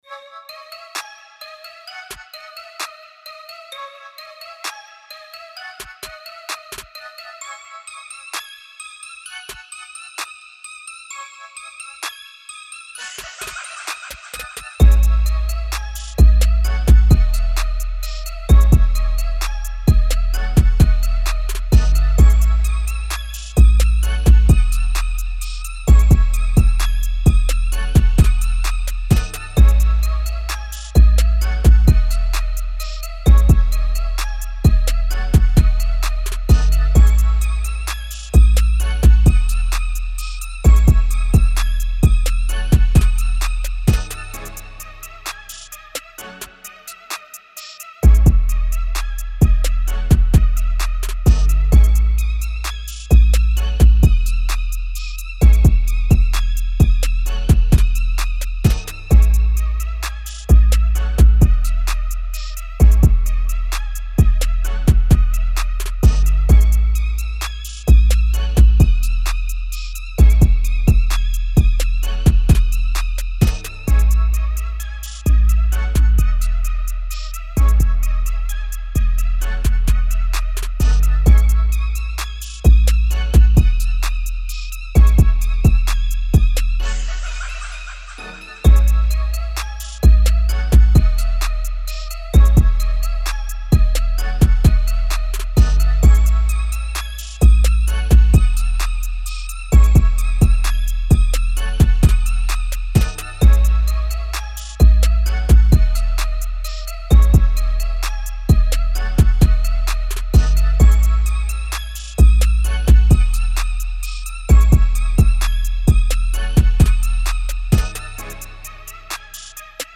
TRAP
C#-Min 130-BPM